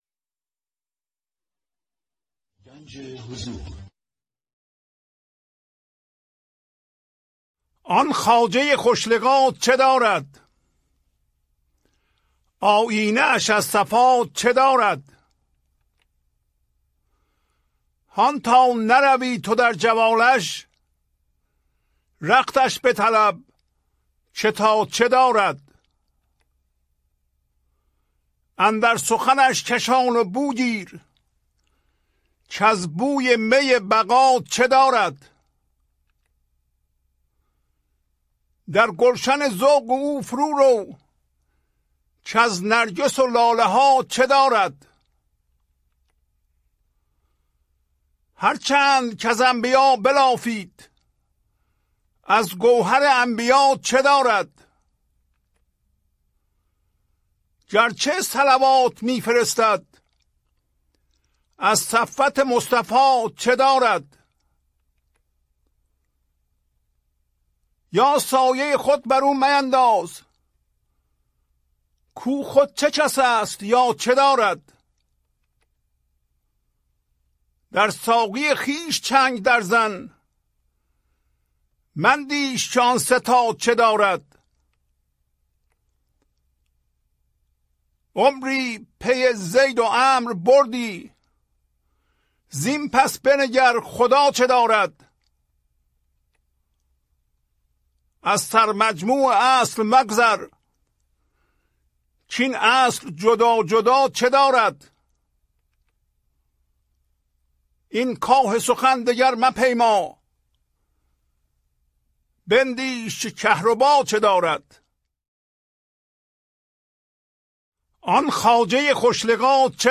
خوانش تمام ابیات این برنامه - فایل صوتی
1039-Poems-Voice.mp3